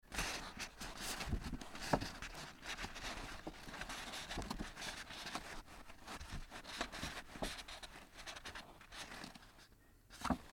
Звуки шуршания бумагой